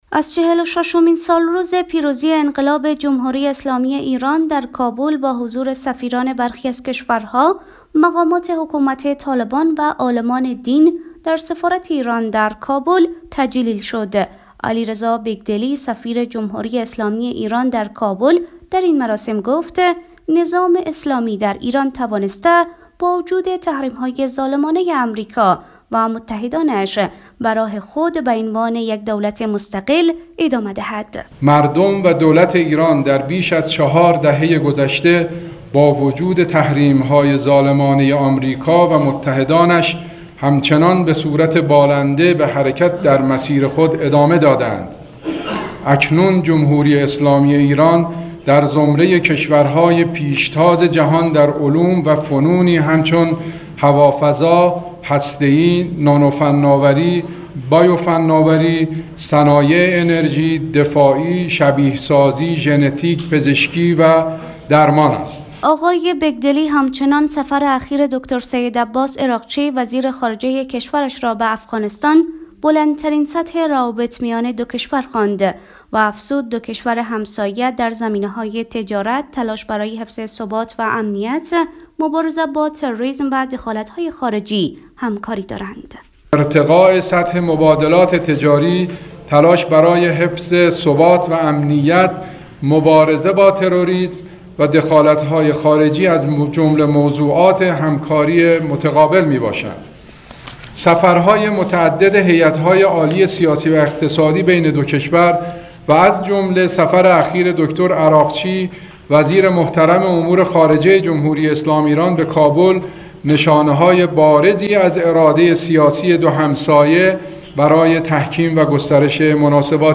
جشن سالگرد پیروزی انقلاب اسلامی ایران روز یکشنبه در کابل با حضور چهره ها و شخصیت های سیاسی افغانستان و سفیران و دیپلمات‌های کشورهای مختلف برگزار شد.